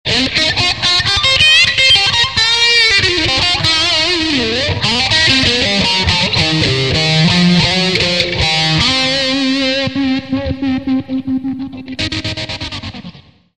Ezen a héten egy kis blues-rögtönzést készítettem. A hangnem: c-moll, bár meg kell jegyezni, hogy a bluesban a moll-, ill. dúr jelleg általában összemosódik.